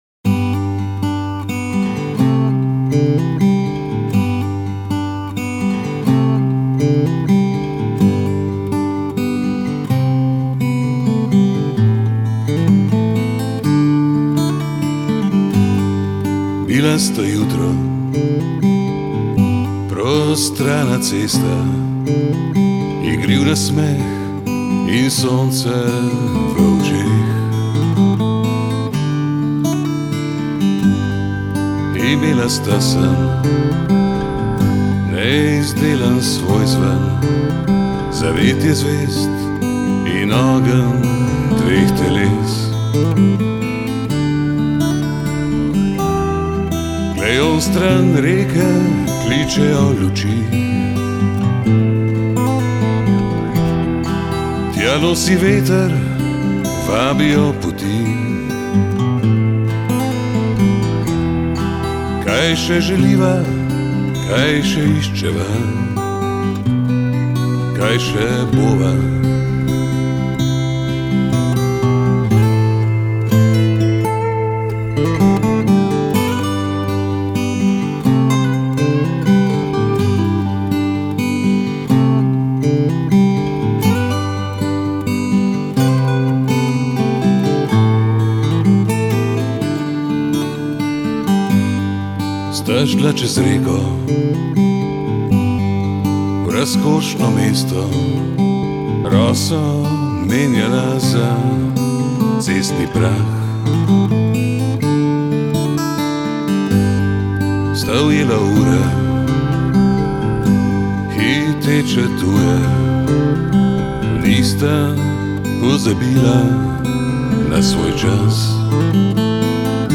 ustvarja intimno vzdušje z glasom in kitaro
ki kombinira peto poezijo z glasbo na akustični kitari.